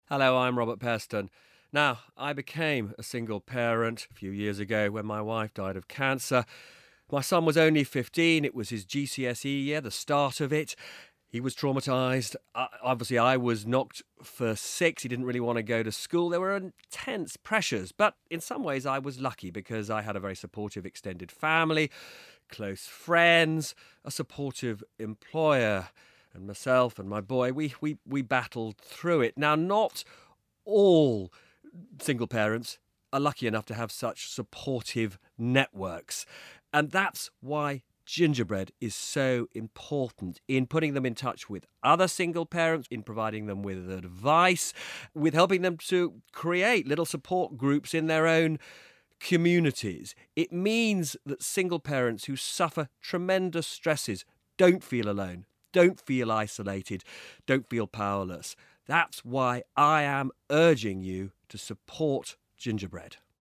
Listen to Robert speak about his experience of single parenthood and why he is supporting Gingerbread: